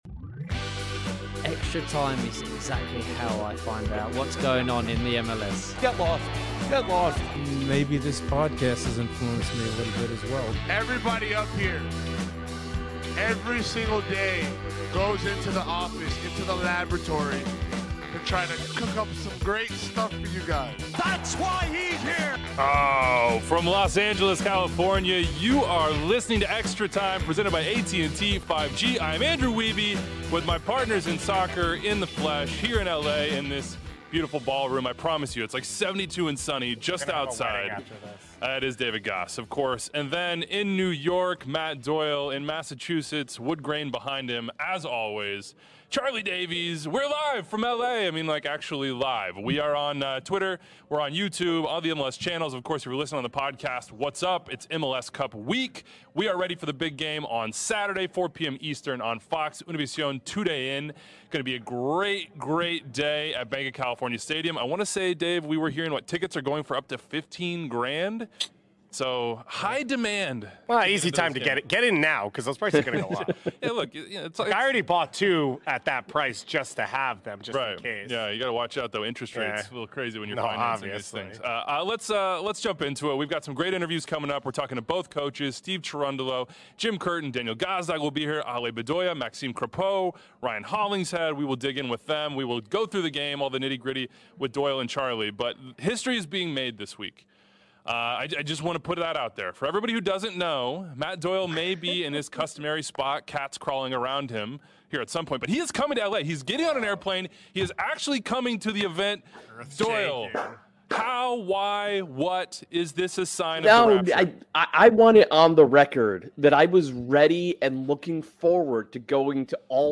Stick around for interviews with both head coaches and players from LAFC and the Union!